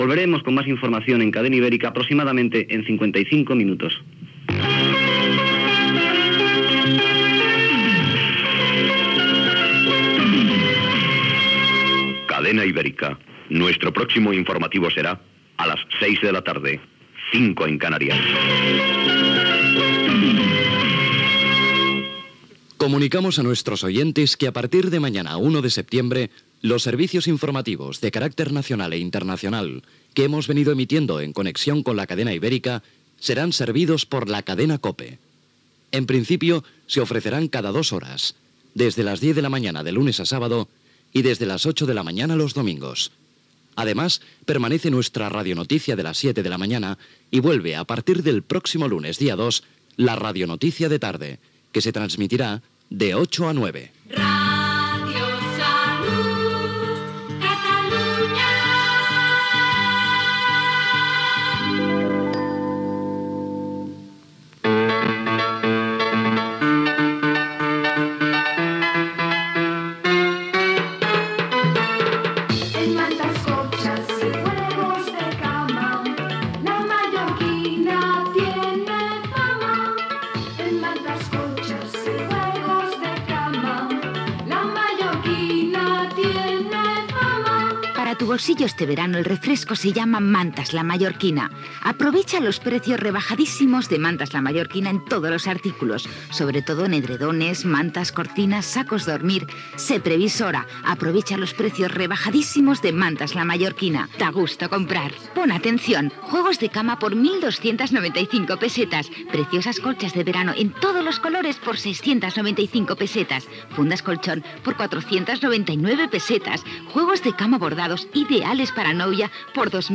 Comiat i sortida de l'informatiu de la Cadena Ibérica, avís que des de l'1 de setembre els informatius es faran en connexió amb la Cadena COPE, publicitat, indicatiu de l'emissora, presentació del programa i d'un tema musical
FM